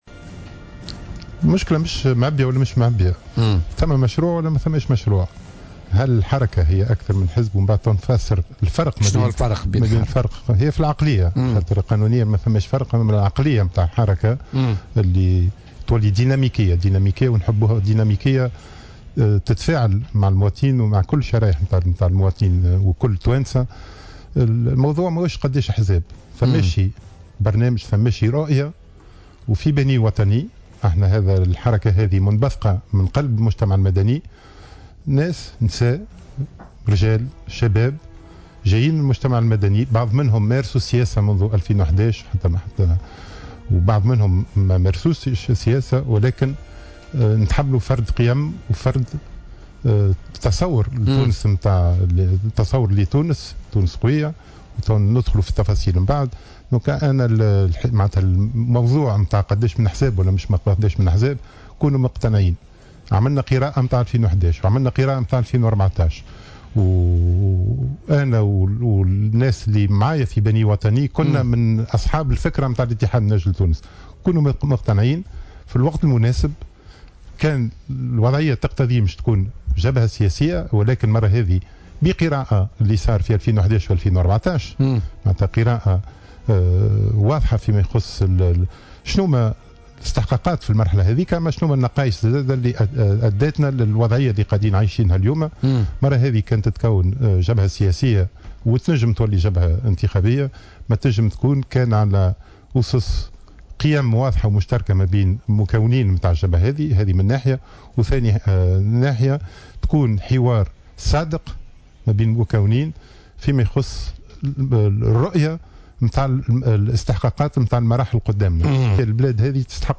وأضاف العايدي، ضيف برنامج "بوليتيكا" اليوم أن البلاد في حاجة إلى جيل جديد وعقلية جديدة في مجال ممارسة السياسة، مشيرا إلى أن الحركة ستضم في صفوفها العديد من الكفاءات وستساهم في إفراز جيل جديد من السياسيين.